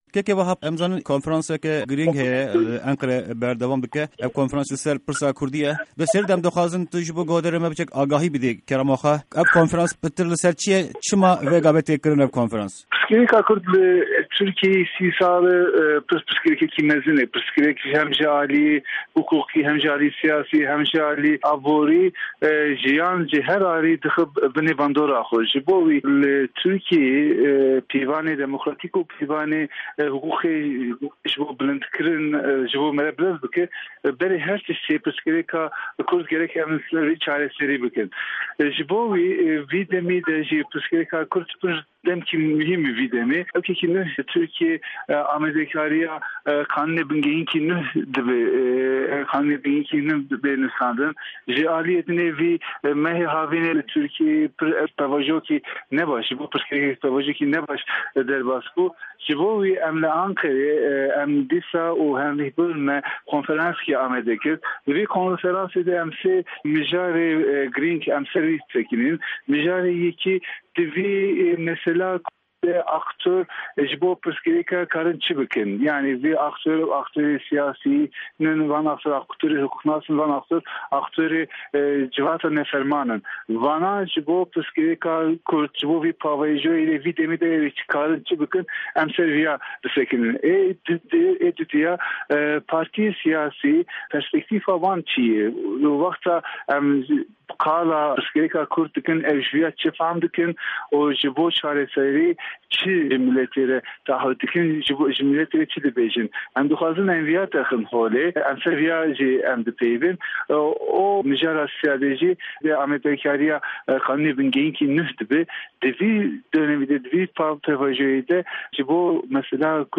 Di hevpyvîna Pişka Kurdî ya Dengê Amerîka